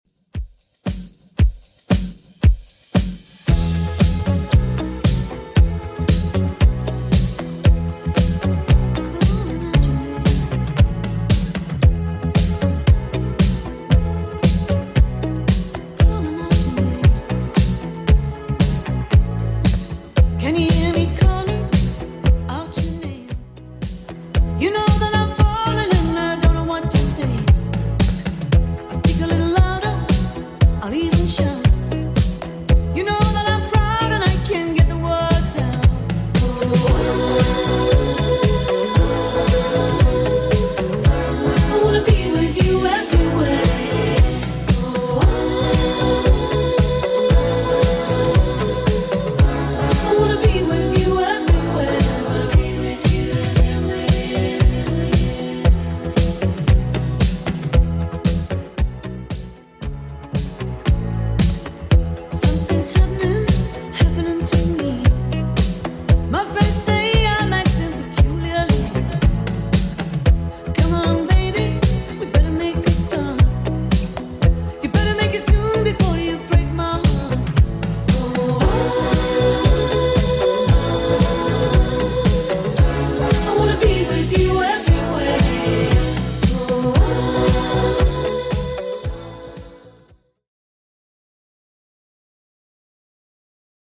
Genre: 90's Version: Clean BPM: 130